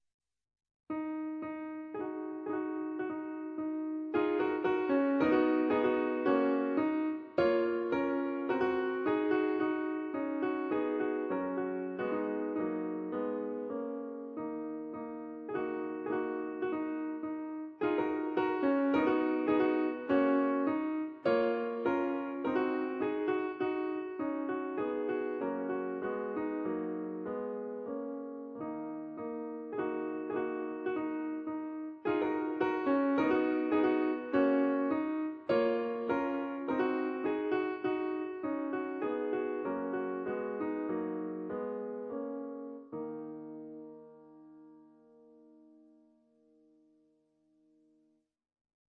ADATTAMENTI PER PIANO